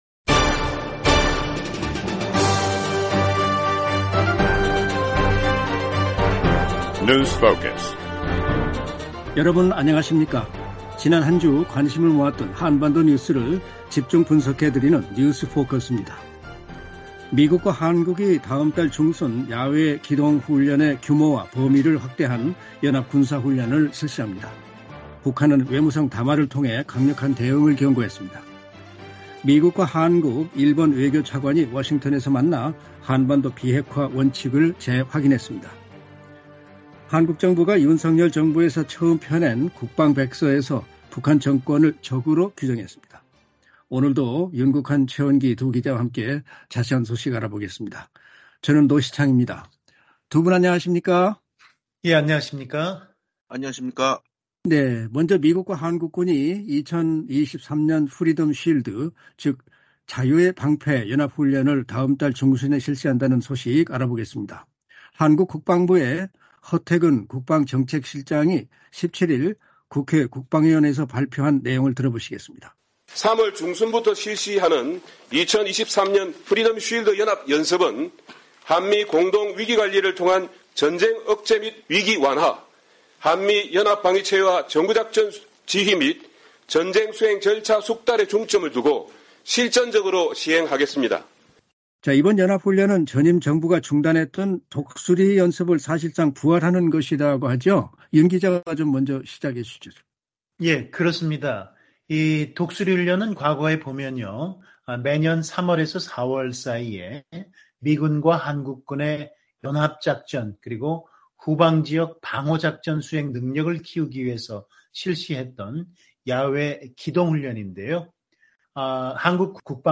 지난 한 주 관심을 모았던 한반도 뉴스를 집중 분석해 드리는 ‘뉴스 포커스’입니다. 미국과 한국이 다음달 중순 야외기동훈련의 규모와 범위를 확대한 연합군사훈련을 실시합니다.